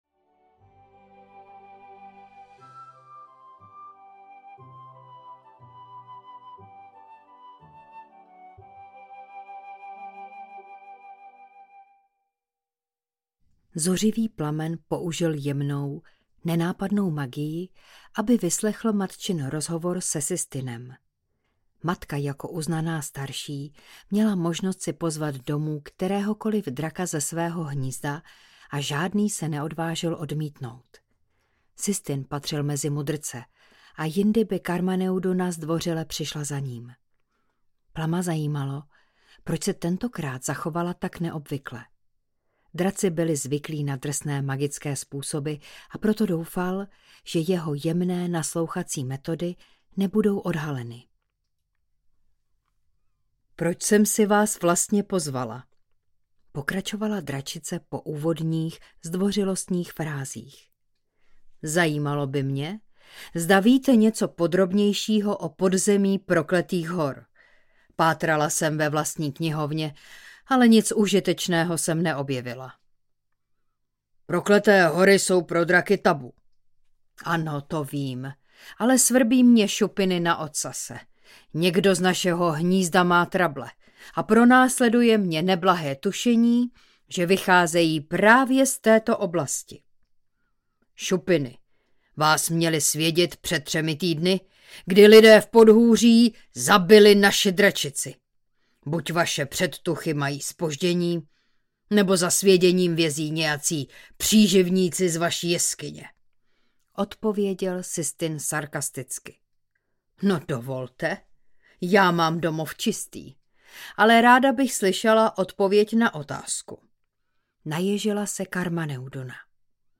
Otrocká krása audiokniha
Ukázka z knihy